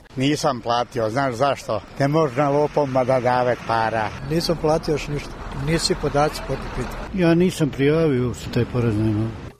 Mišljenje građana: